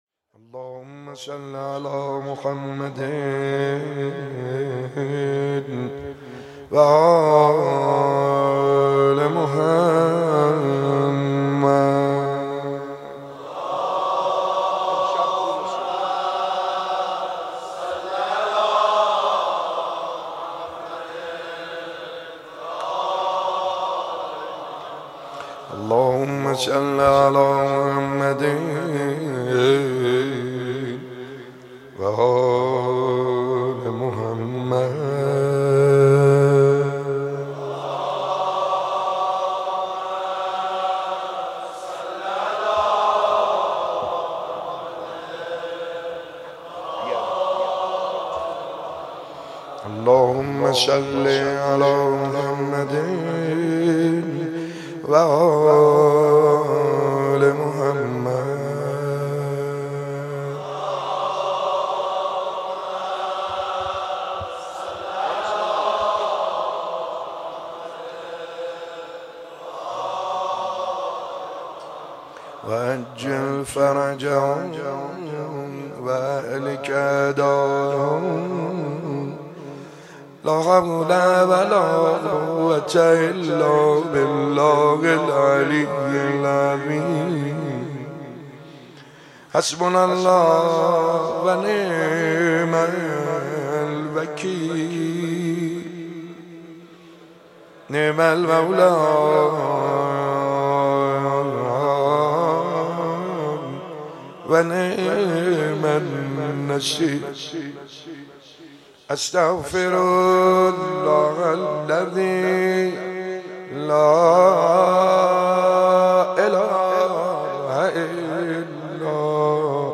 شب هفتم ماه رمضان 95_مناجات خوانی